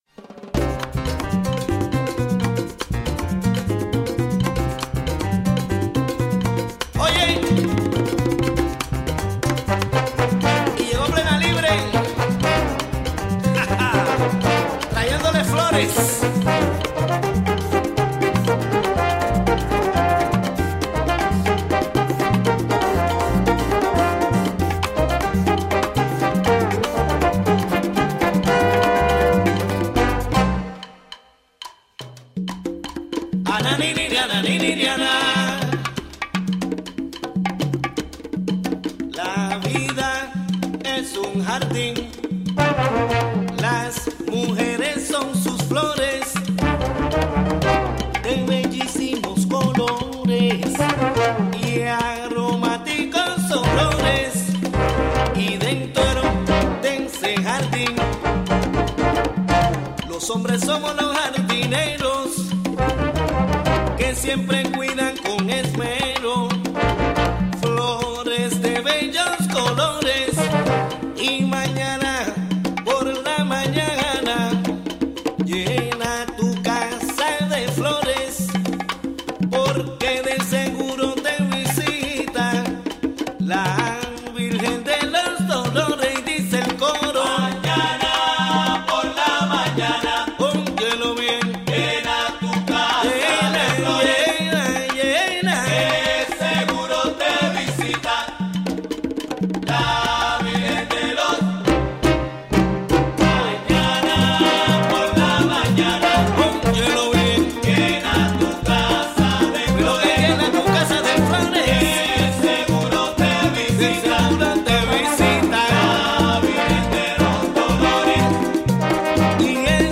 Interviews with two groups organizing Mexican and Central American independence festivals in the Hudson Valley.